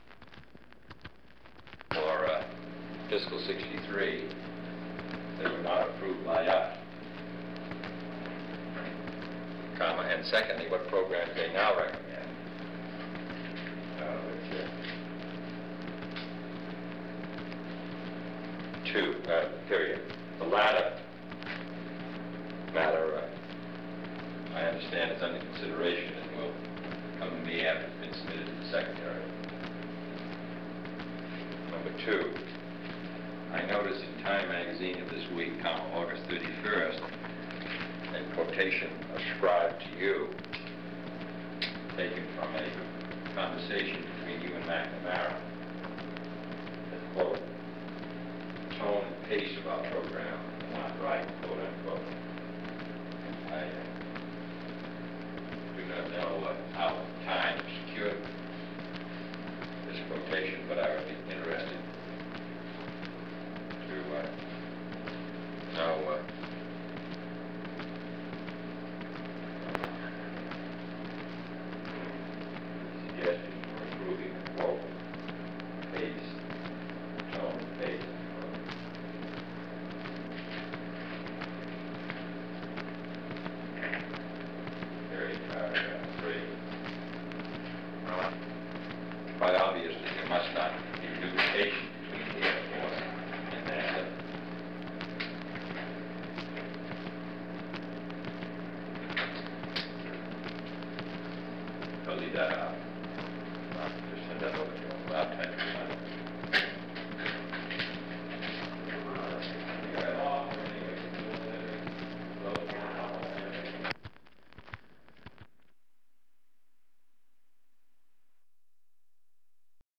Dictated Memo to Eugene Zuckert
Secret White House Tapes | John F. Kennedy Presidency Dictated Memo to Eugene Zuckert Rewind 10 seconds Play/Pause Fast-forward 10 seconds 0:00 Download audio Previous Meetings: Tape 121/A57.